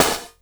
50SD  02  -R.wav